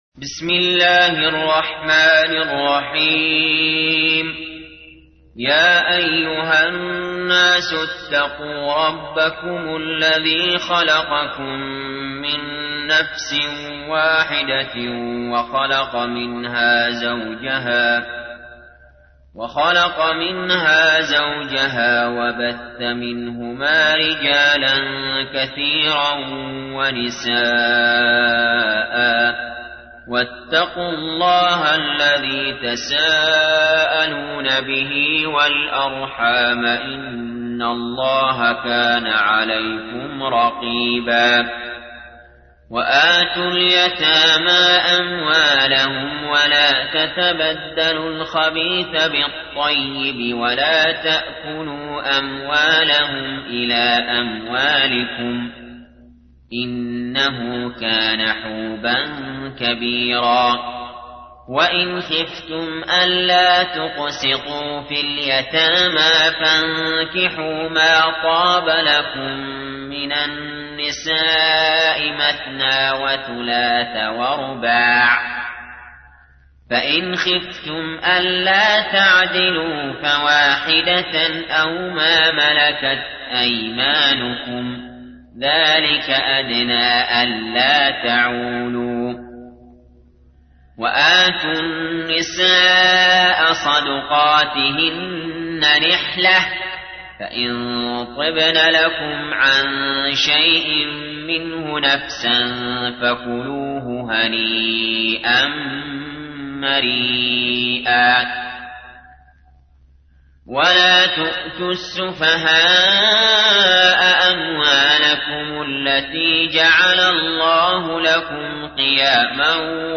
تحميل : 4. سورة النساء / القارئ علي جابر / القرآن الكريم / موقع يا حسين